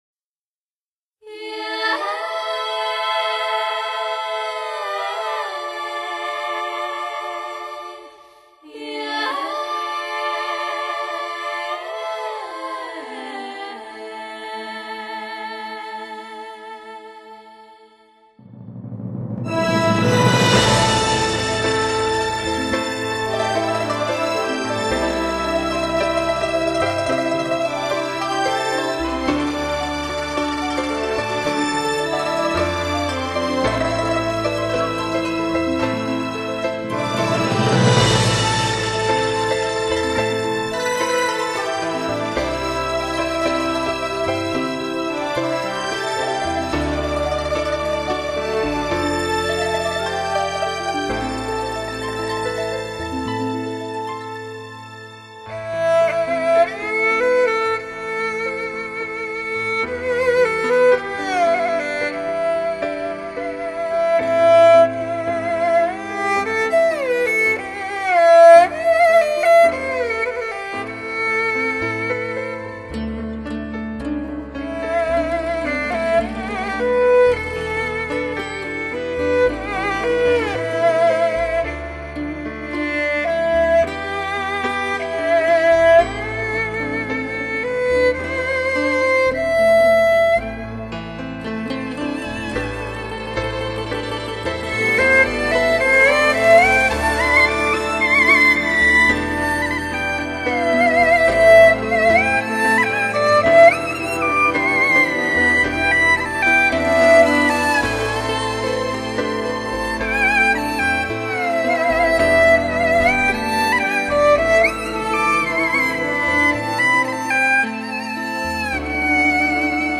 一触即发的低音狂潮，凭借领先国际的DTS多声道环绕和多重混响技术，带给你极尽奢华的磁性音质和前所未有的听觉感受。
器乐版